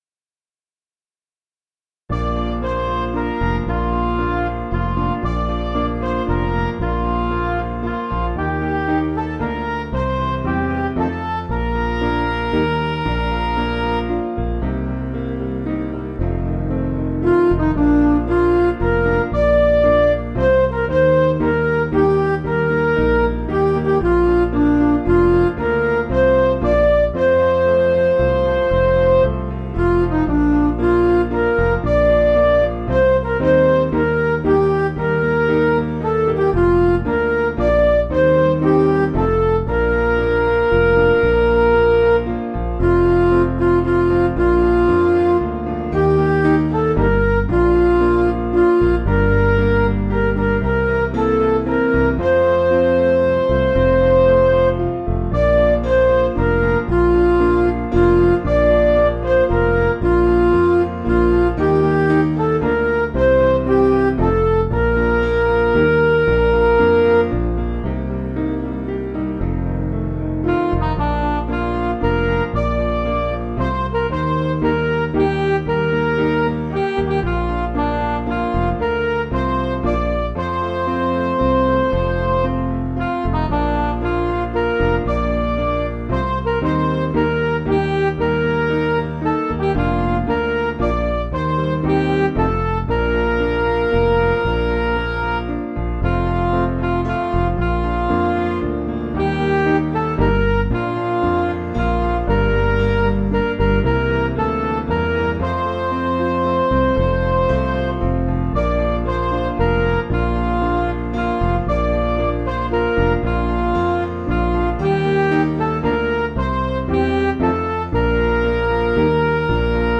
Piano & Instrumental
(CM)   3/Bb
Midi